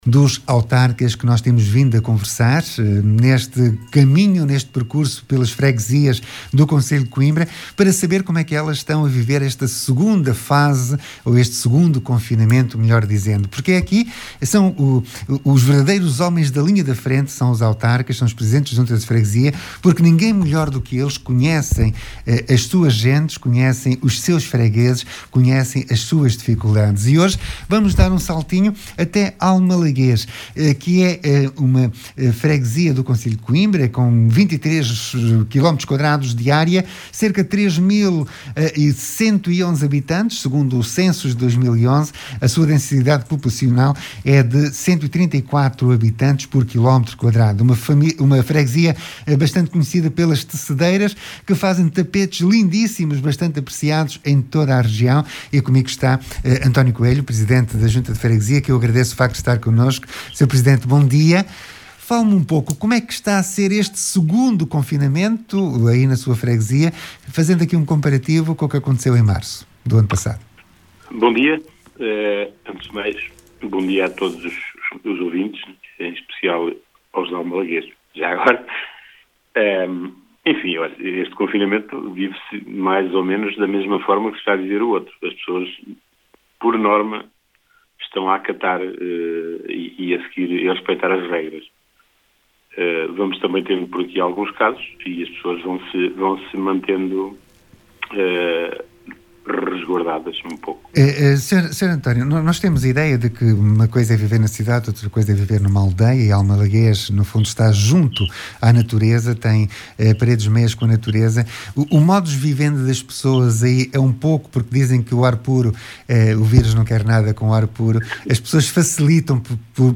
Em conversa com o seu presidente, António Coelho, ficámos a saber como está a ser este 2º. confinamento. Falou-nos das dificuldades, do apoio que têm recebido da Câmara Municipal, entre outros assuntos.